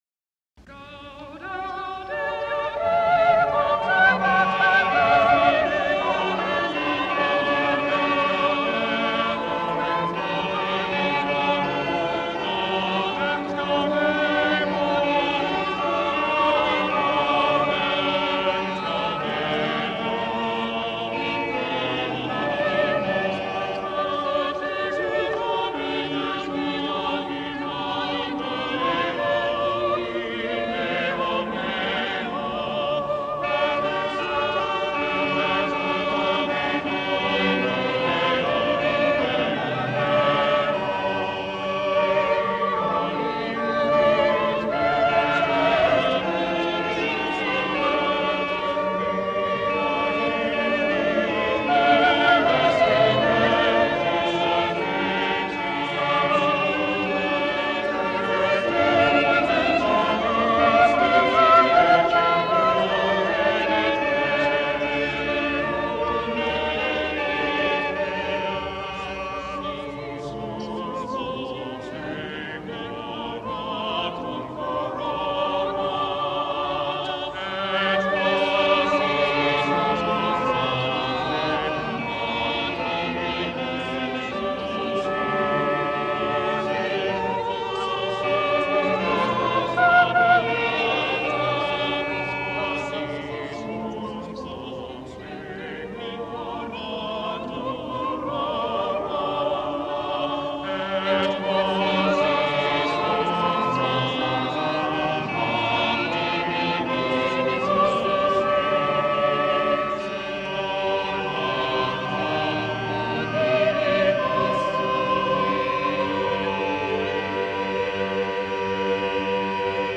The motet is a5 with two alto parts, recorded in the remarkable acoustical space of St. John’s Abbey Church, Collegeville, MN.